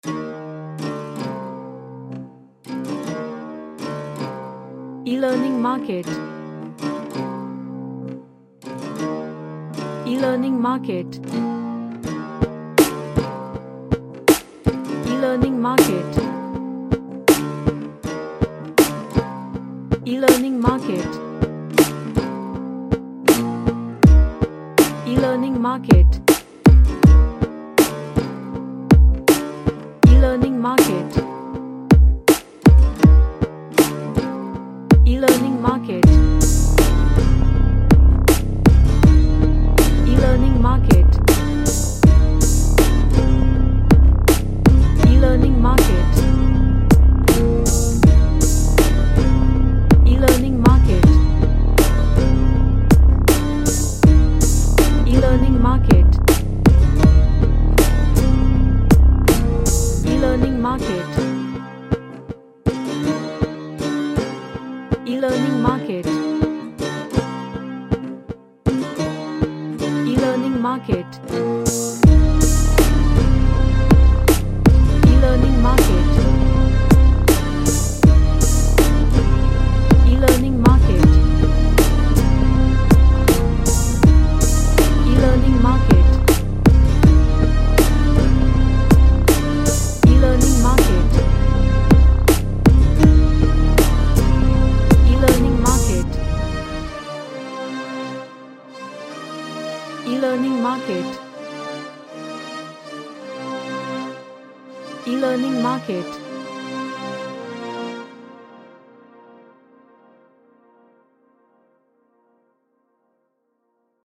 A tight sounding dark pop track
Dark / Somber